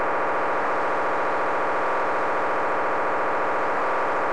Each can be identified by the distinct sound of its data channel:
motorola.wav